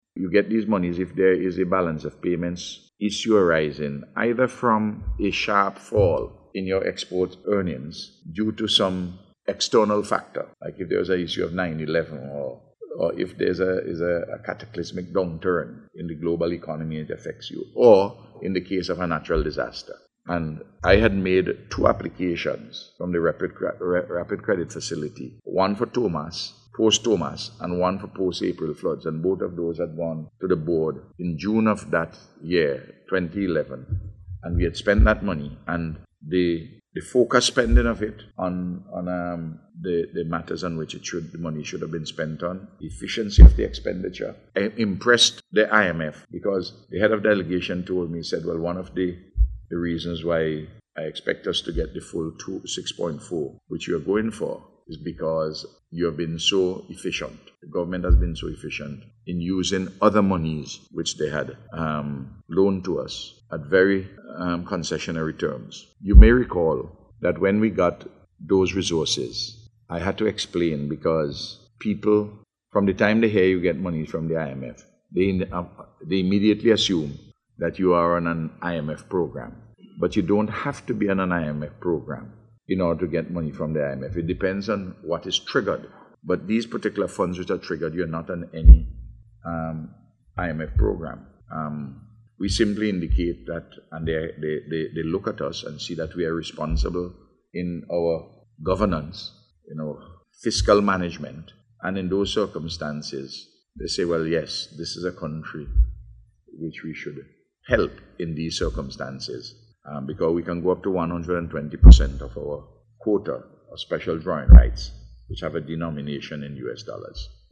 Speaking at a news conference this morning, Dr. Gonsalves said this disbursement is expected to assist this country in meeting an urgent balance-of-payments need due to severe flooding and landslides in December 2013 that caused massive damage to infrastructure, housing and agriculture.